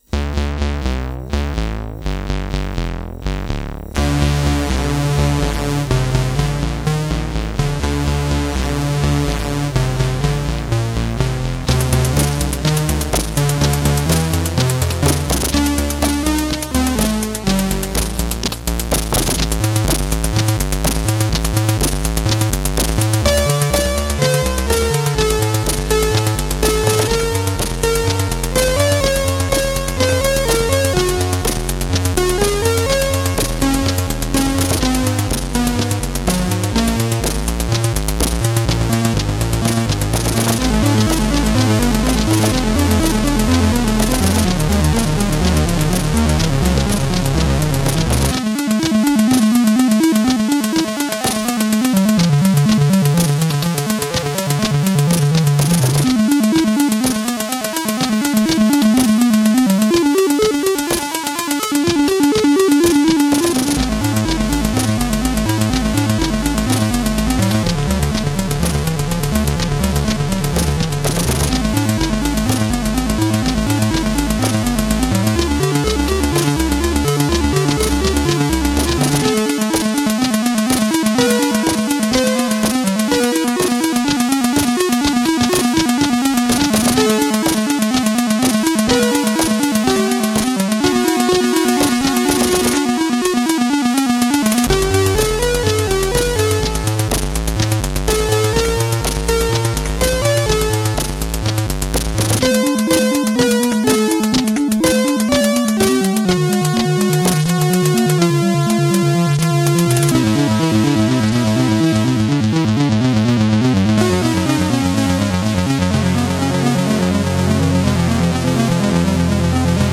I worked some hours to get the drumsounds.